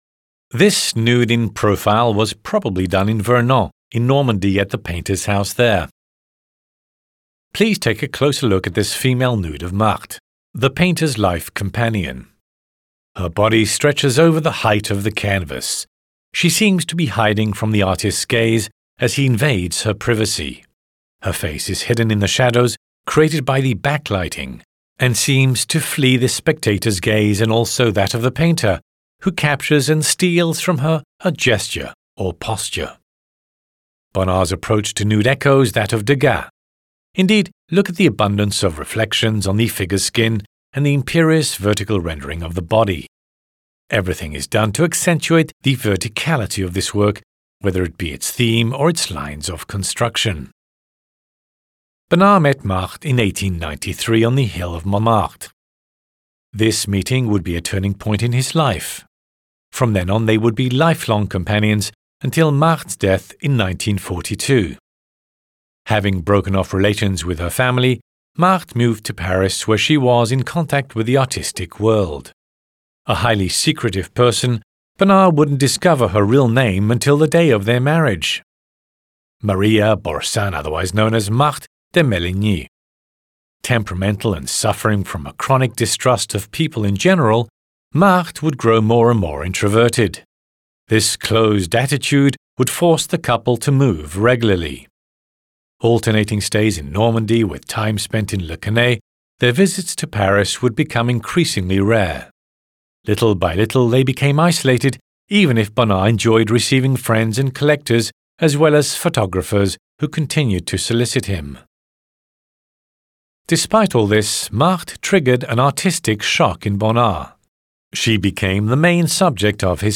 Les audioguides de la Collection